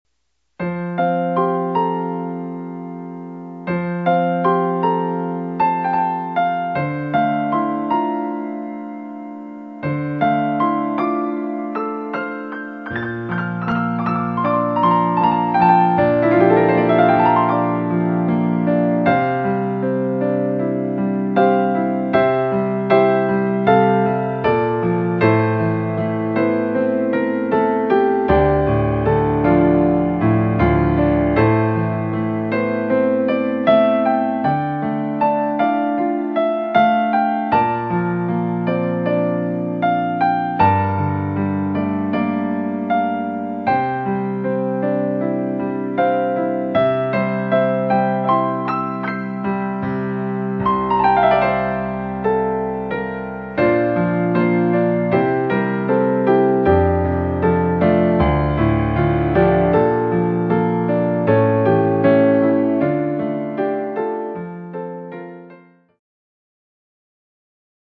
今のところ、ピアノのみの試し弾き、絵に例えれば白黒のスケッチのようなものです。